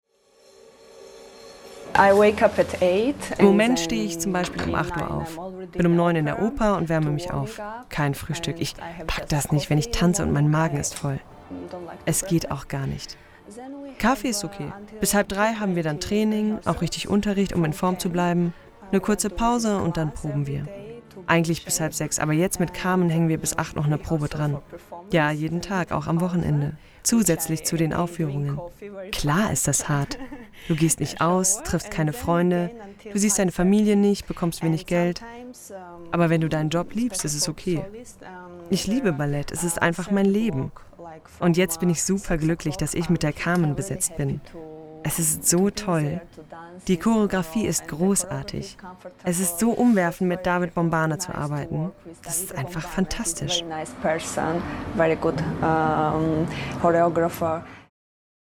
Tief, freundlich, warm
Sprechprobe: Sonstiges (Muttersprache):
Voice-Over.mp3